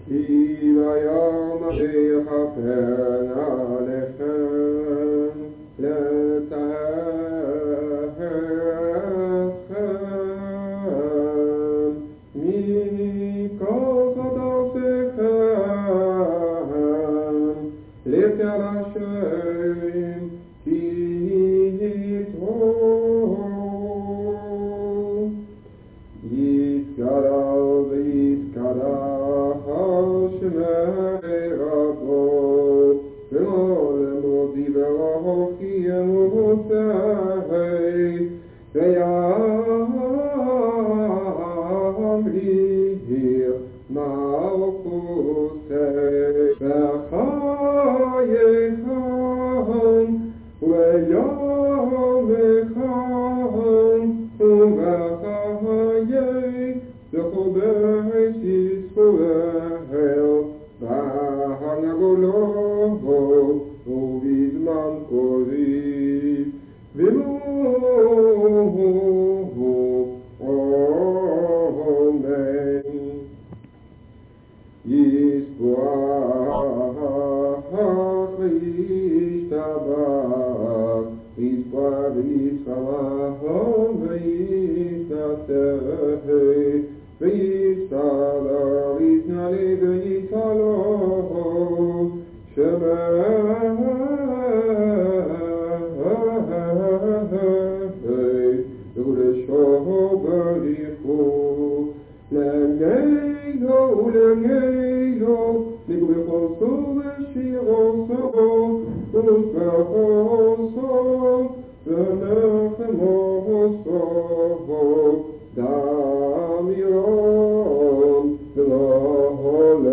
op cassettebandjes.